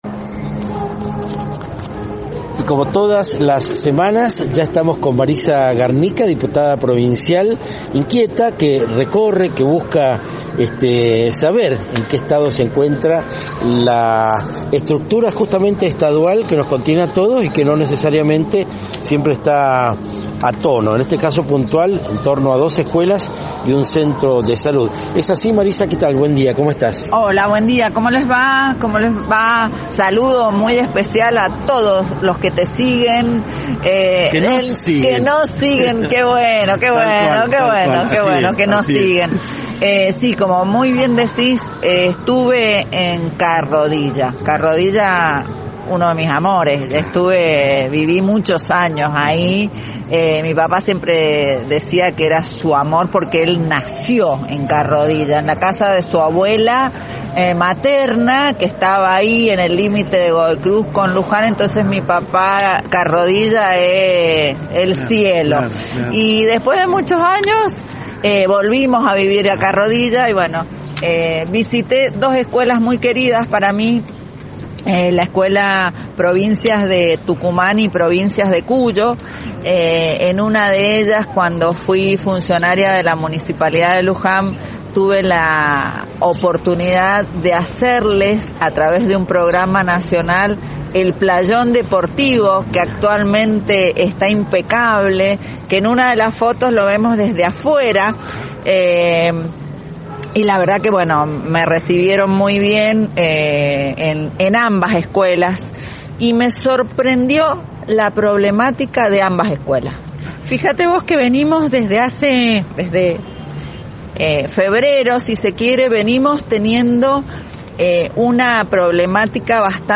Entrevista: Marisa Garnica, Diputada Provincial, 15 de setiembre de 2022